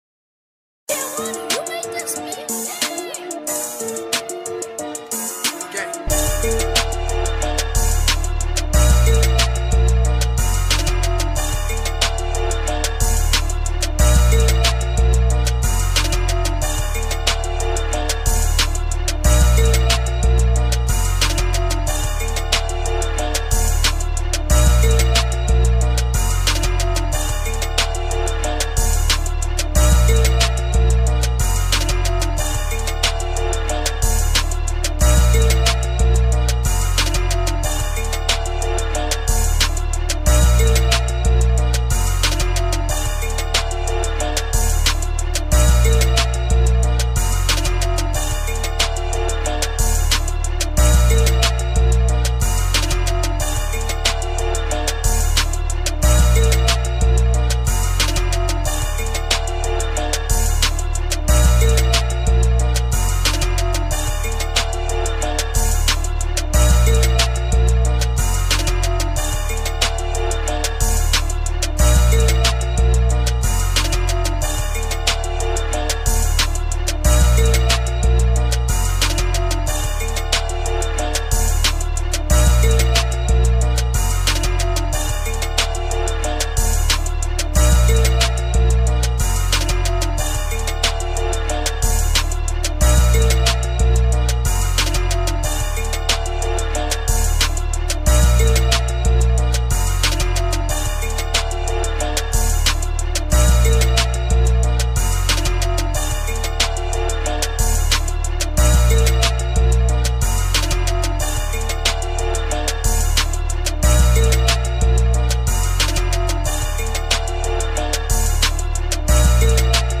hip hop beat